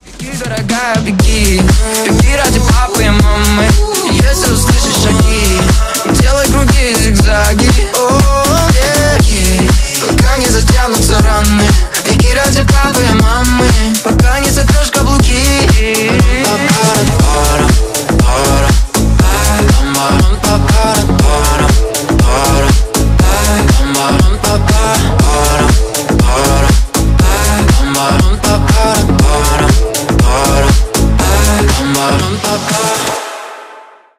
• Качество: 320, Stereo
мужской голос
энергичные
house
ремиксы